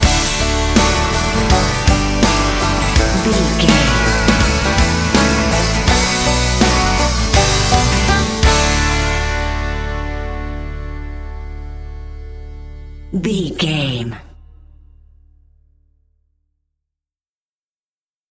Ionian/Major
D
drums
electric piano
electric guitar
bass guitar
banjo
country rock
bluegrass
happy
uplifting
driving
high energy